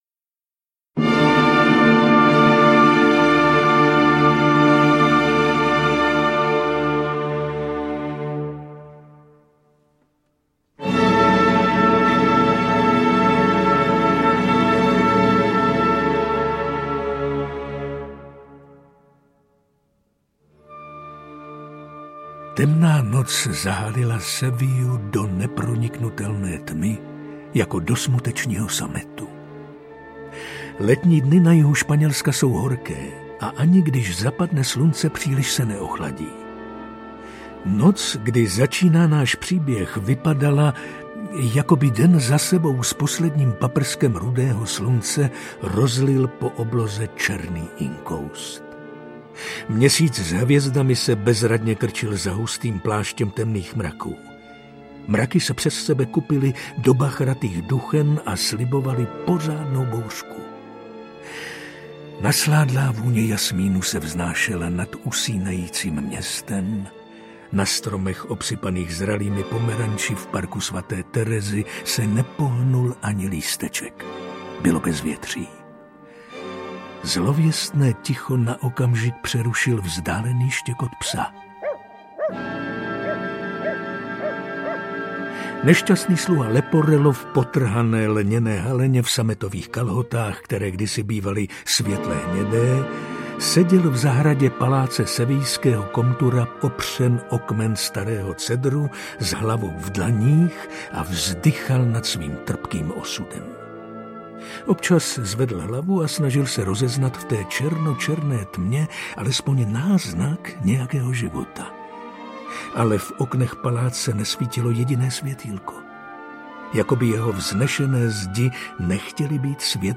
Ukázka z knihy
Na tyto otázky vám odpoví dramatizace Jana Jiráně podle libreta Lorenza da Ponte v podání známých českých herců s ukázkami hudebních pasáží jednoho z nejslavnějších děl světové operní literatury.